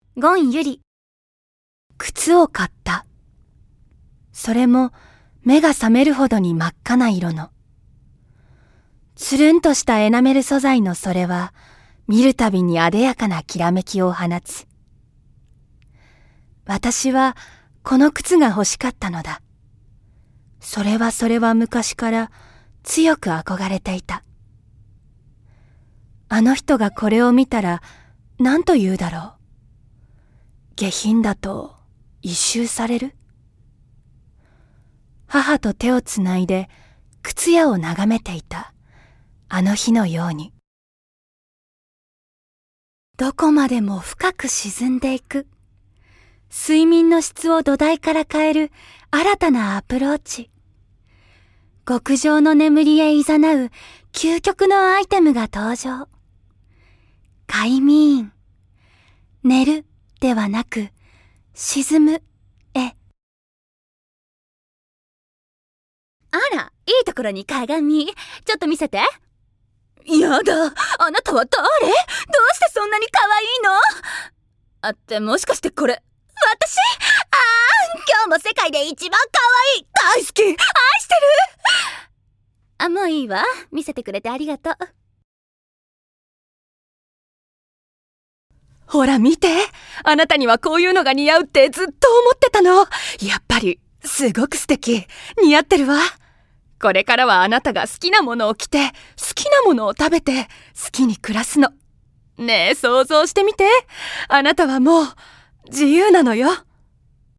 ◆ナレーション／外画